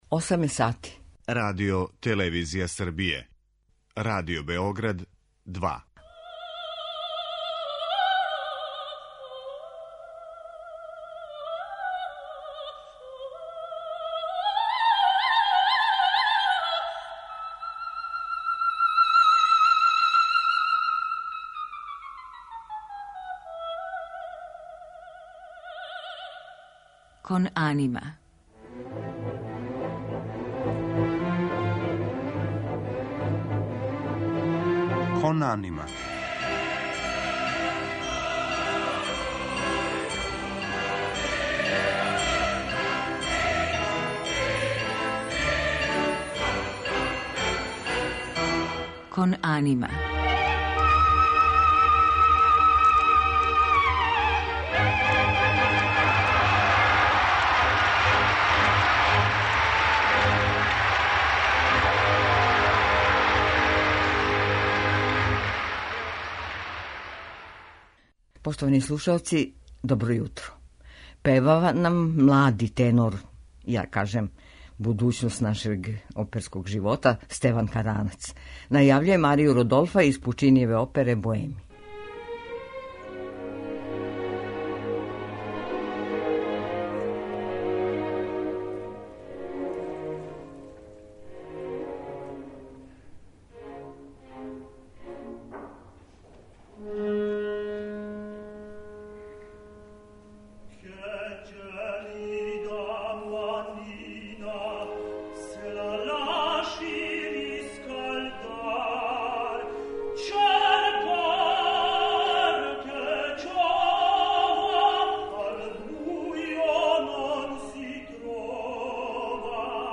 У музичком делу биће емитоване арије из наведених опера у извођењу овог нашег веома перспективног младог уметника.